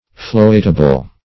Floatable \Float"a*ble\, a. That may be floated.